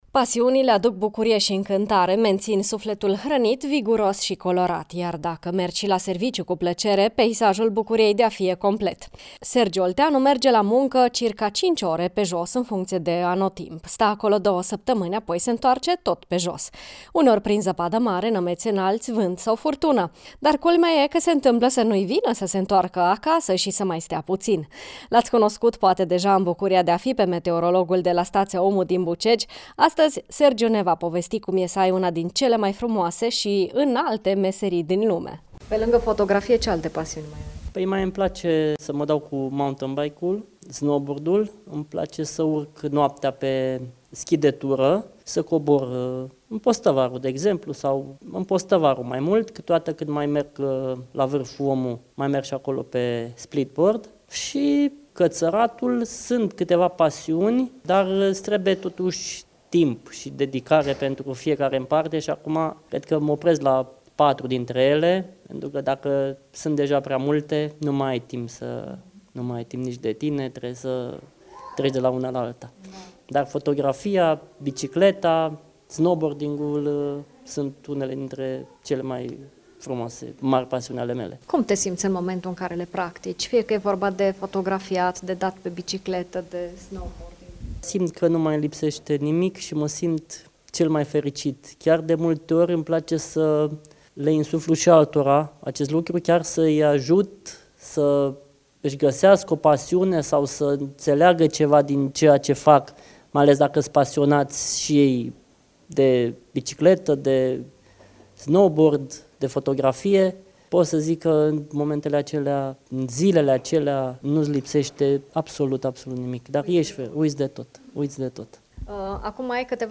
Partea a doua a interviului